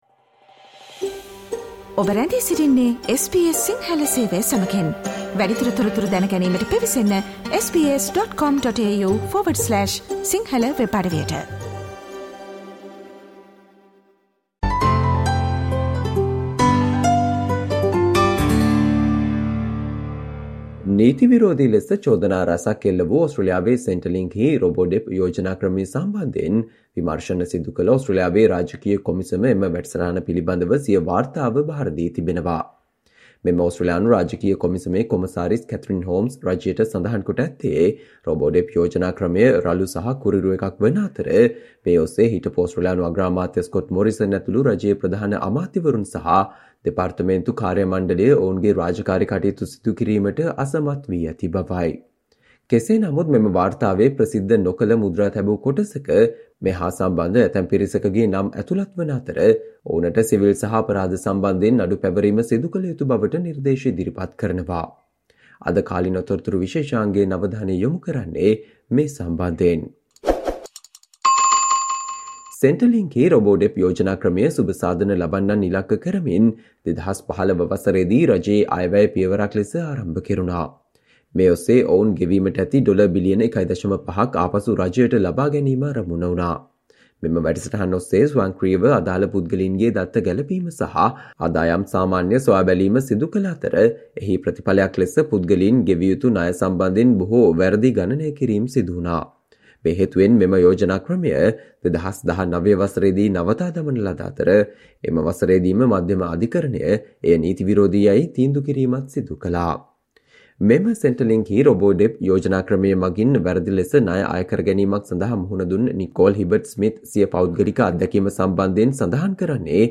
Today - 10 July , SBS Sinhala Radio current Affair Feature on Allegations on problems with internet and mobile phone service companies in Australia